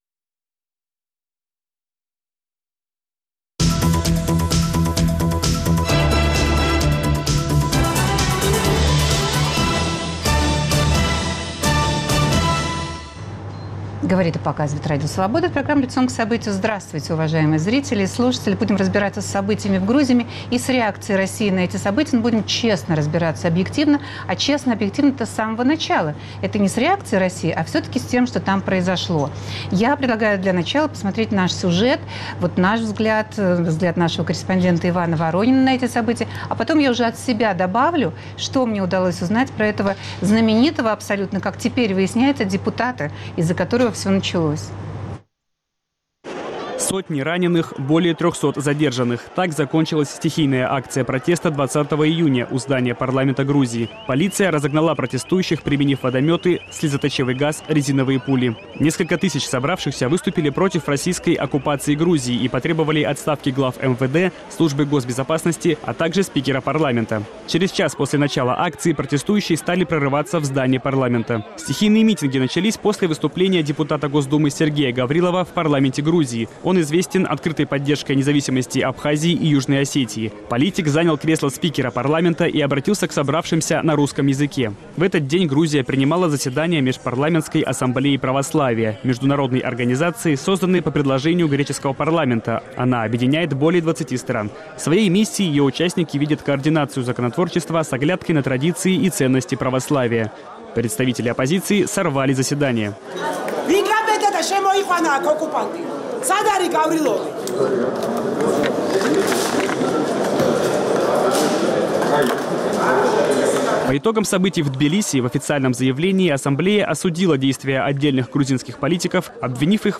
Можно ли считать адекватной реакцией на события в Грузии отмену авиасообщения между Москвой и Тбилиси? Разговор о том, что на самом деле стоит за путинскими решениями.